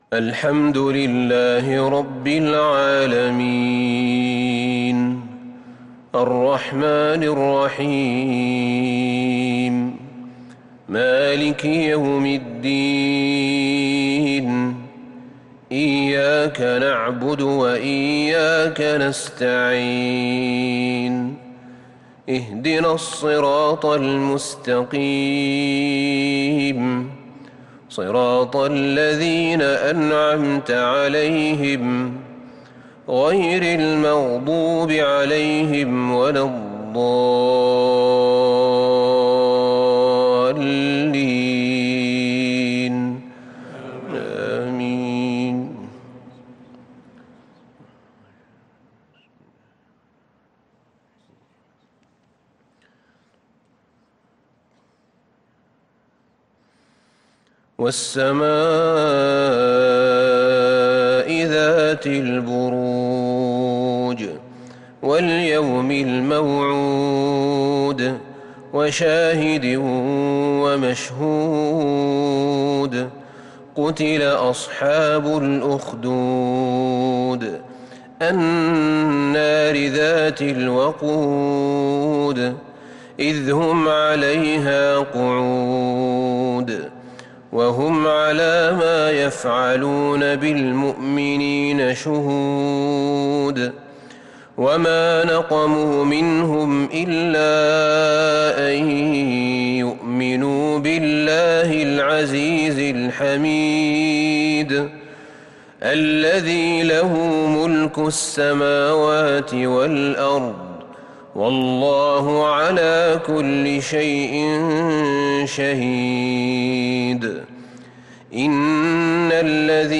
صلاة العشاء للقارئ أحمد بن طالب حميد 19 محرم 1443 هـ